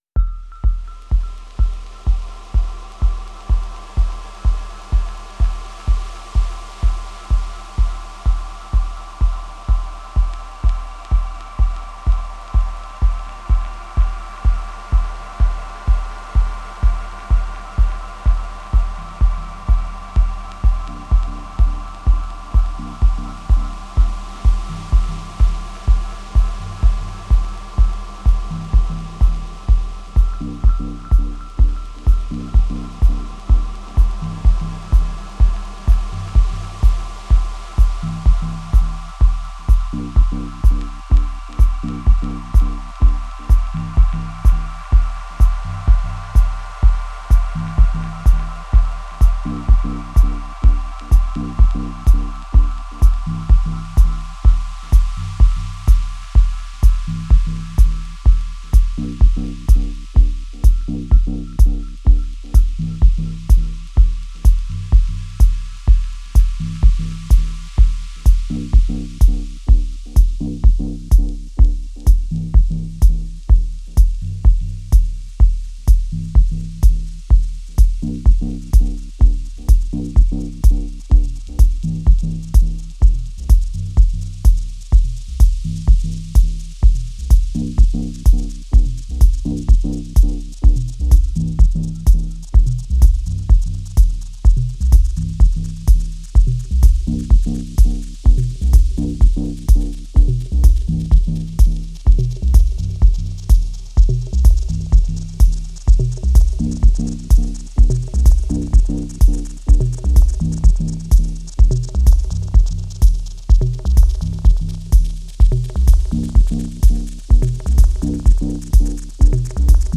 Genre: Tech House, Techno, Minimal.